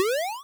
bip_06.wav